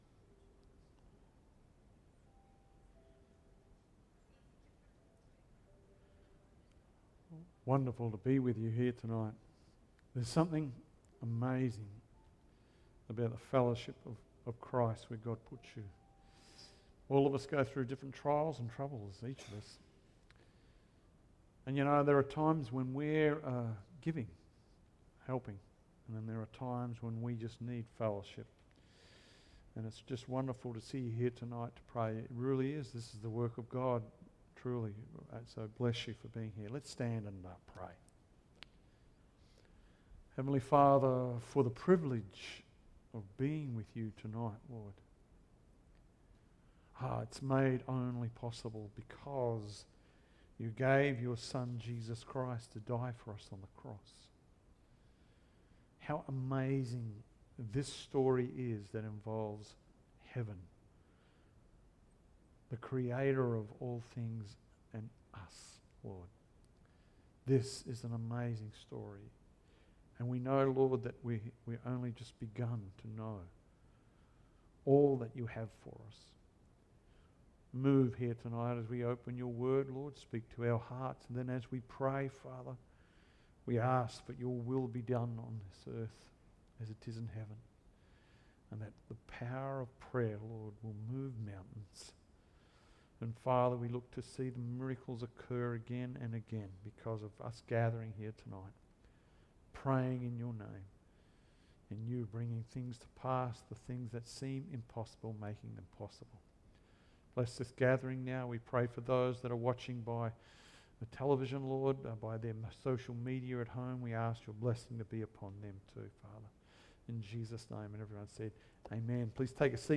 Wednesday Night Prayer – Genesis 45 – The Life of Joseph Part 4 | Calvary Chapel Secret Harbour